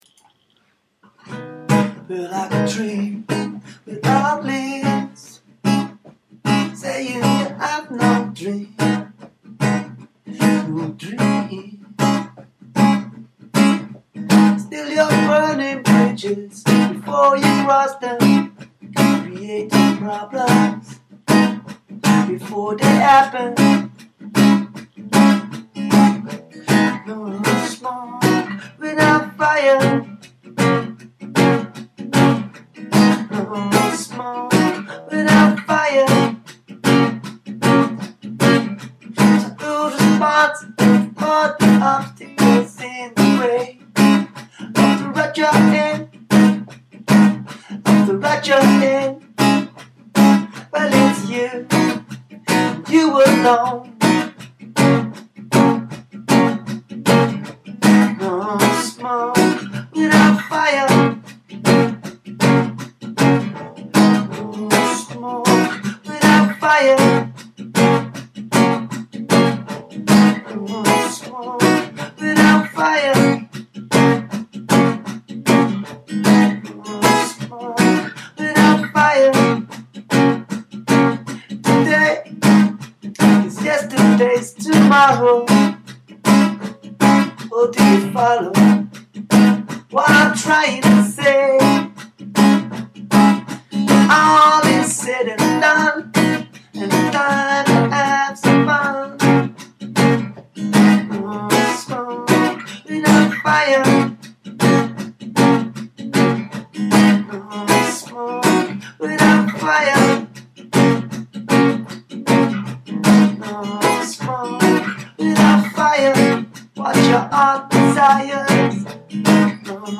G#m C#mG#m E B G#m F#